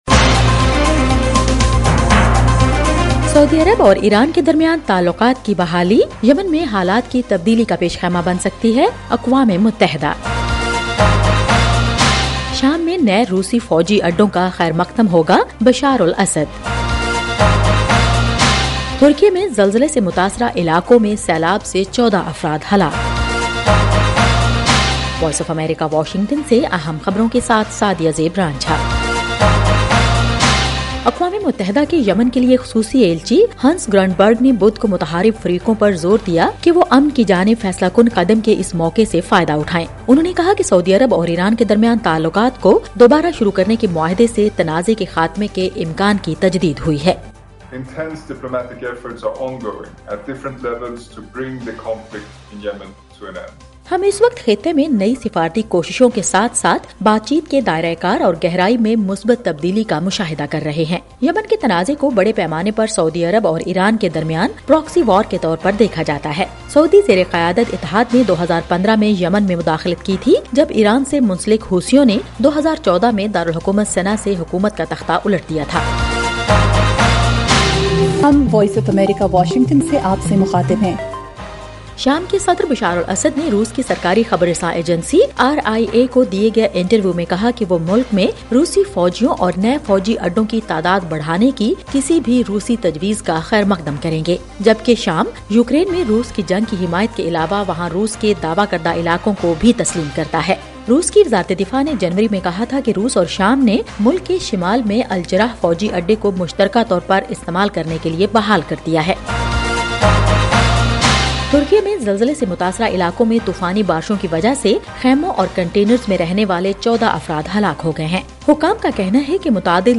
ایف ایم ریڈیو نیوز بلیٹن: رات 10 بجے